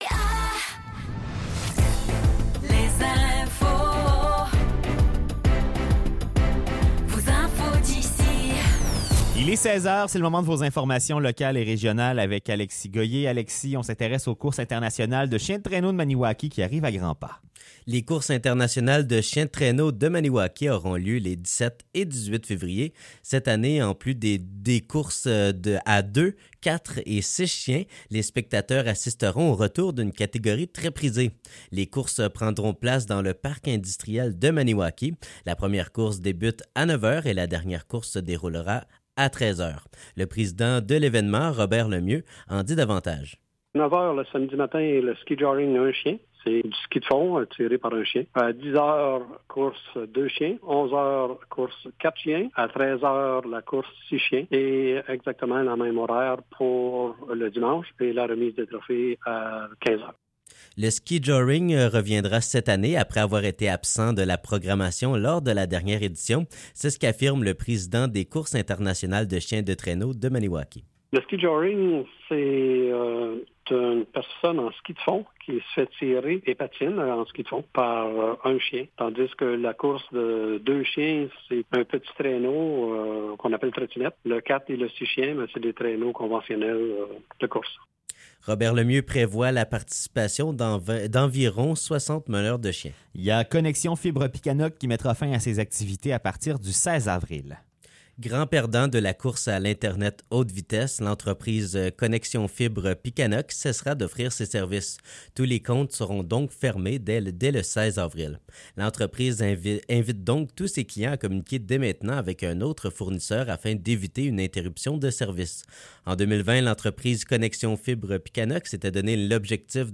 Nouvelles locales - 29 janvier 2024 - 16 h